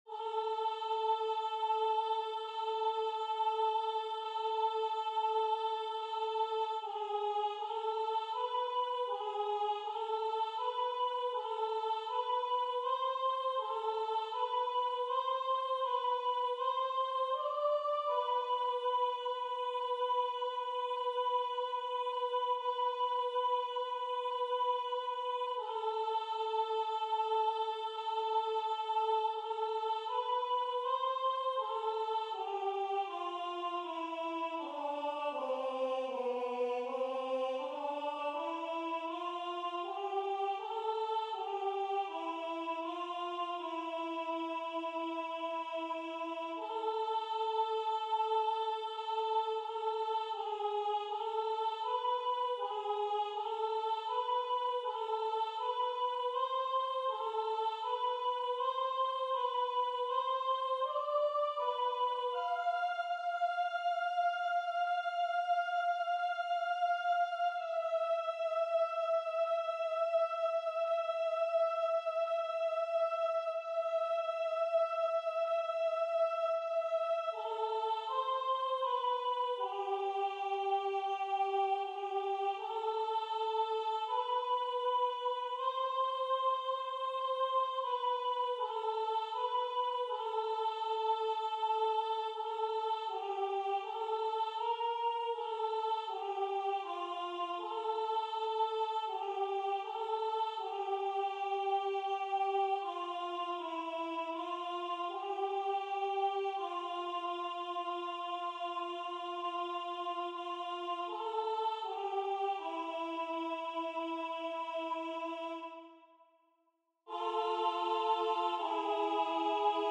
- Œuvre pour chœur à 6 voix mixtes (SAATBB) a capella
MP3 rendu voix synth.
Soprano